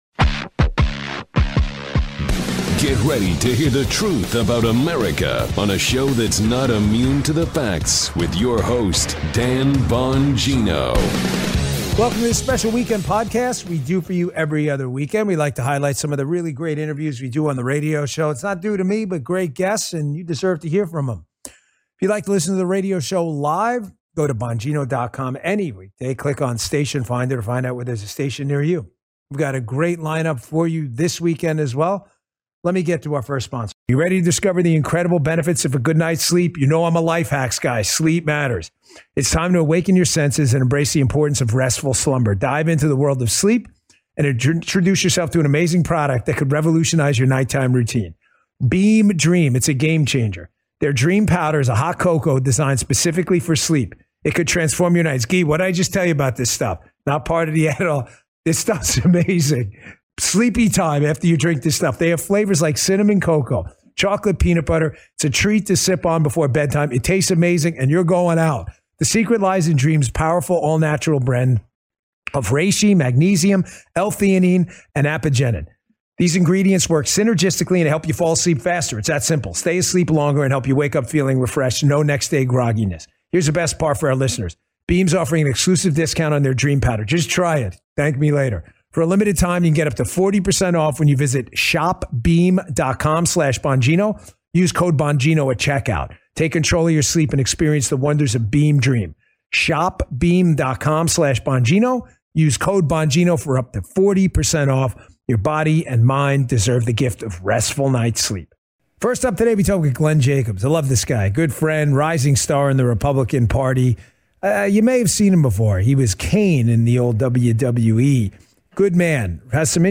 ➡ The text is an excerpt from a radio show hosted by Dan Bongino where he interviews interesting guests and also promote products. This specific episode talks about Beam Dream, a sleep supplement, and has a special guest - Glenn Jacobs, a former WWE wrestler-turned-Republican Mayor of Knox County sharing his journey from wrestling to politics and his belief in American opportunity and conservative values.